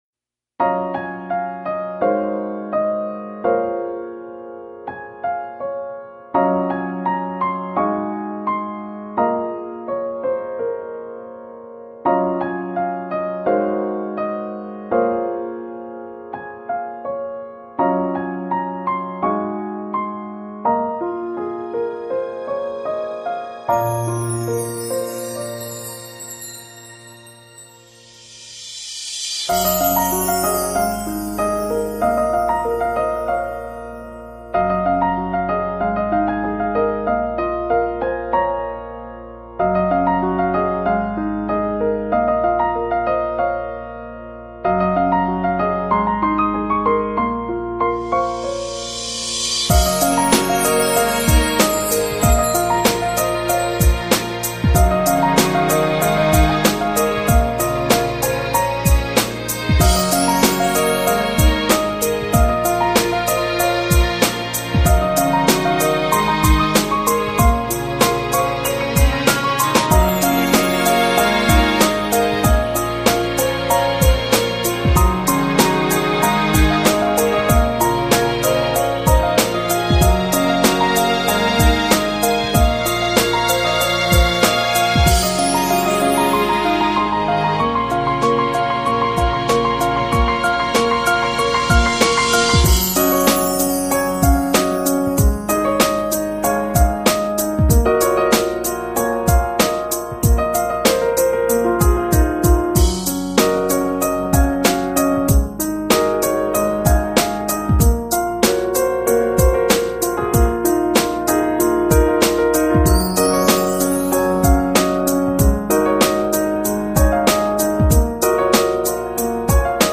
钢琴和电子鼓的结合，开始很缓和 接着节奏开始有跳跃感，很喜欢中间那段的转折慢慢的升华，很美妙的感觉。